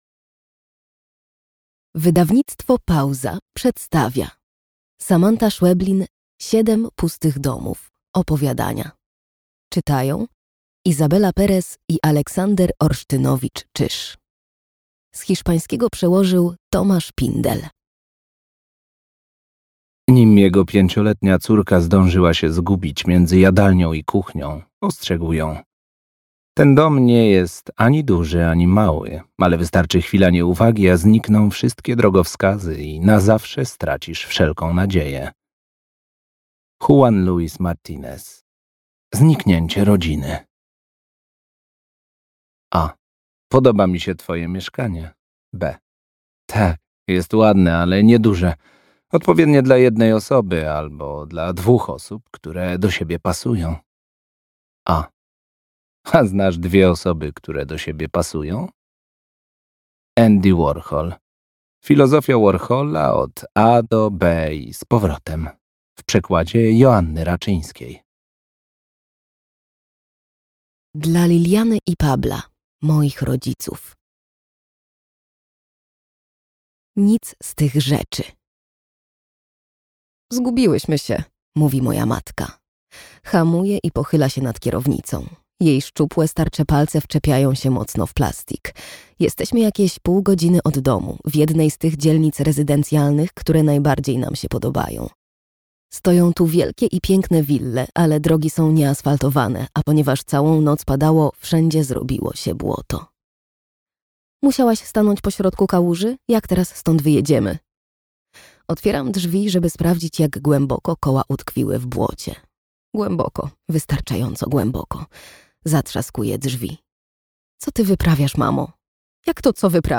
Siedem pustych domów (audiobook) – Wydawnictwo Pauza
AUDIOBOOK